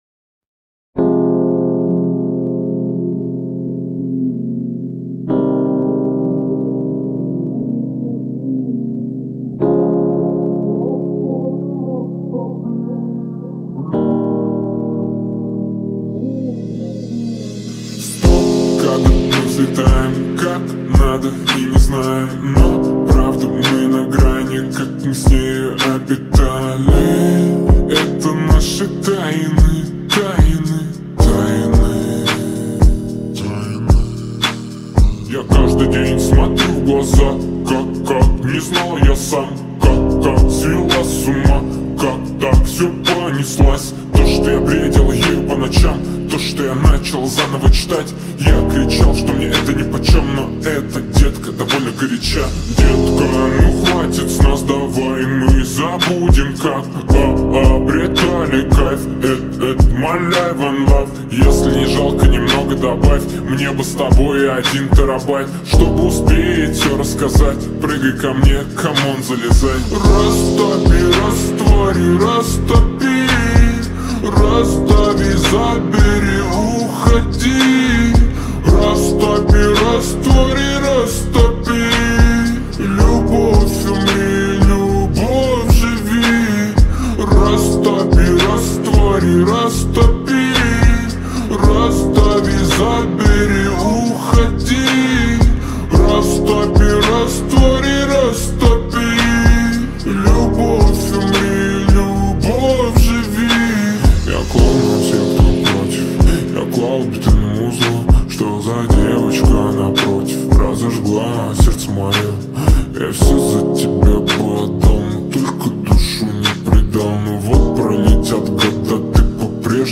TikTok remix